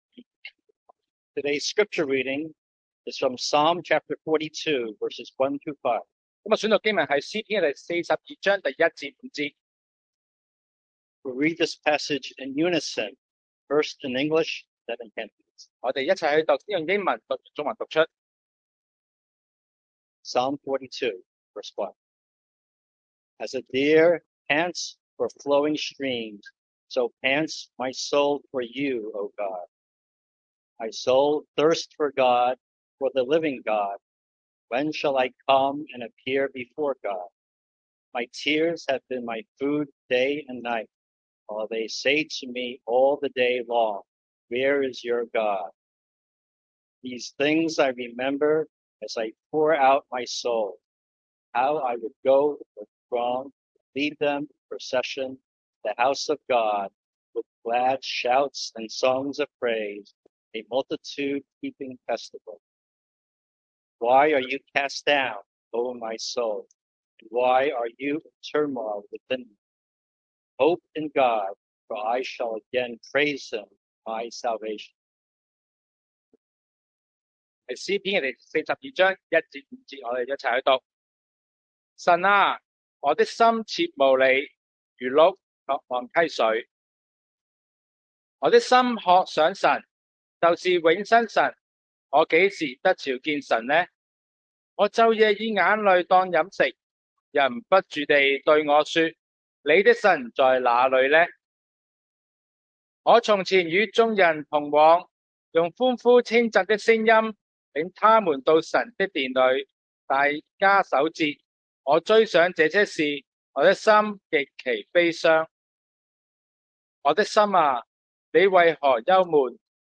Passage: Psalm 42:1-5 Service Type: Sunday Morning